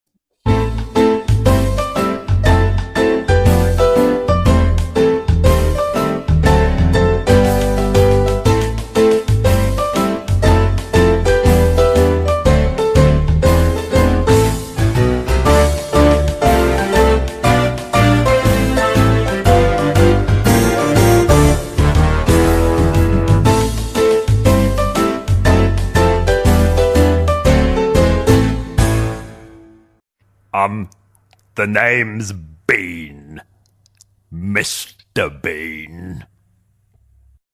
speaking